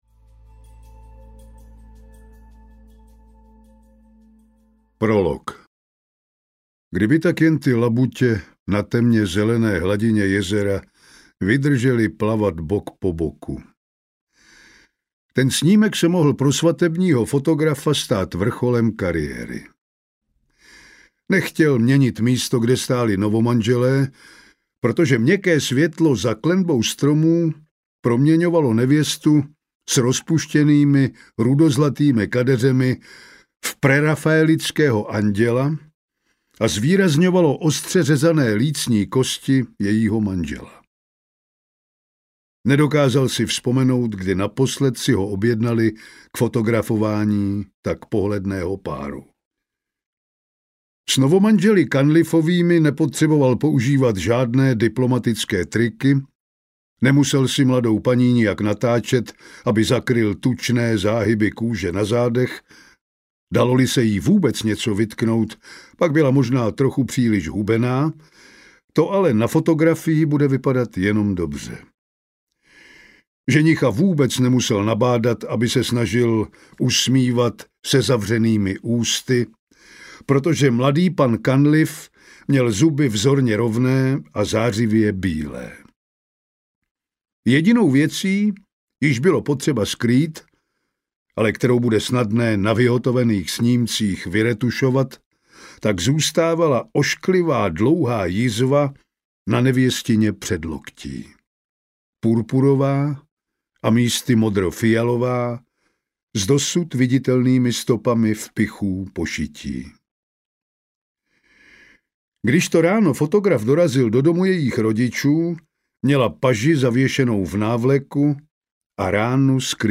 Smrtící bílá audiokniha
Ukázka z knihy